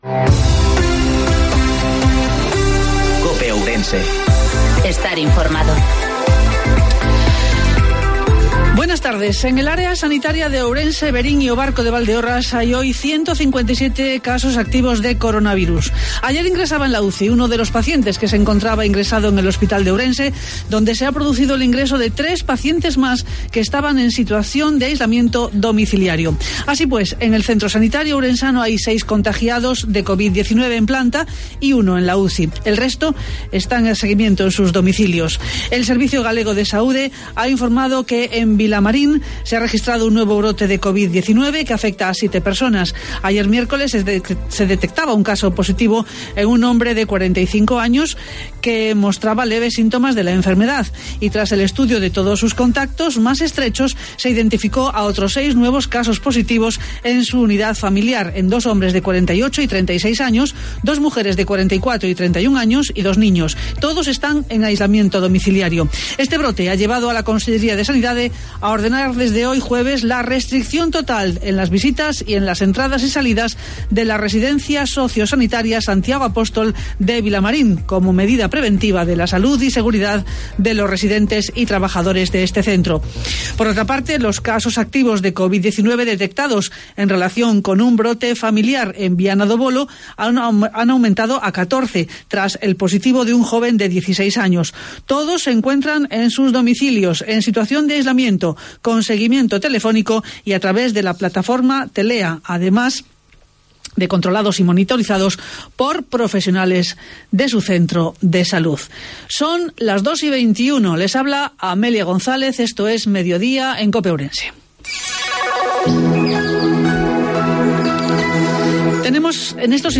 INFORMATIVO MEDIODIA EN COPE OURENSE